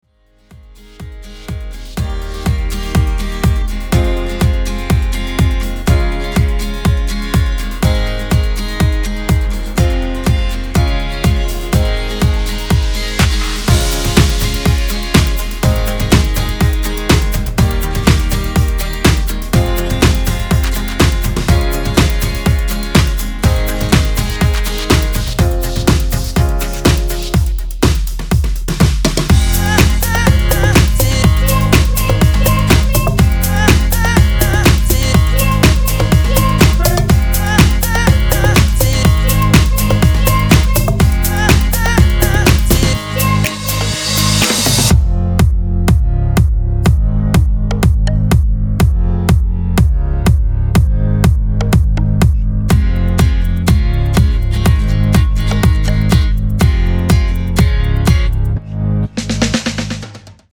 Demo in Db